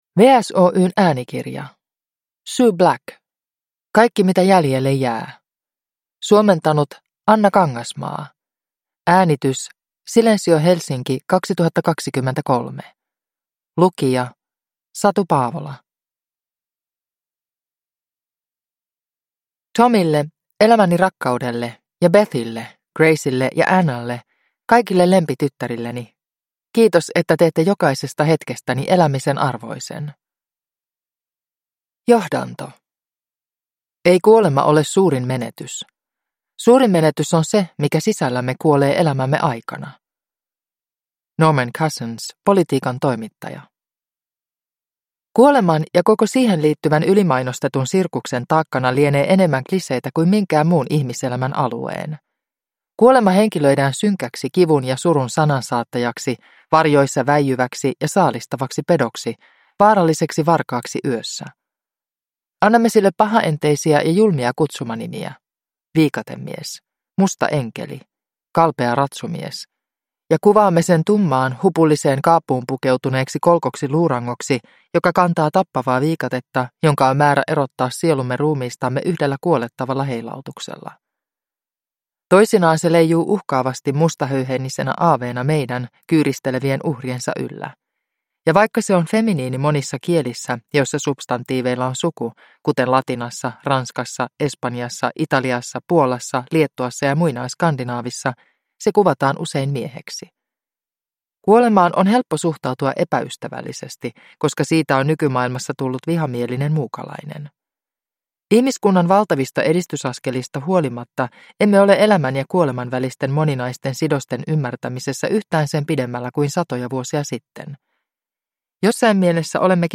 Kaikki mitä jäljelle jää – Ljudbok – Laddas ner